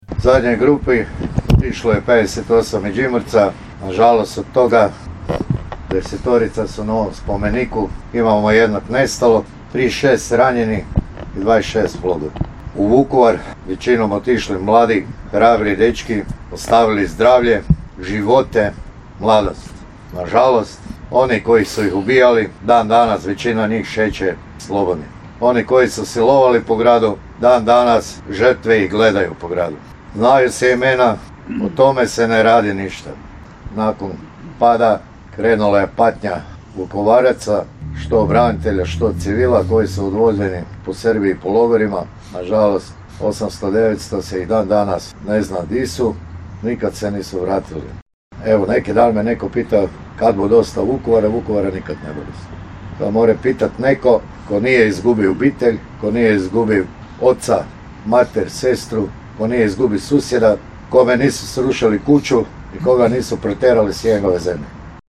Diljem Vukovarske ulice upaljeni su lampioni, a počast braniteljima koji su život položili na oltar Domovine odana je kod spomen-obilježja smrtno stradalim i nestalim hrvatskim braniteljima Domovinskog rata Međimurske županije u Perivoju Zrinski.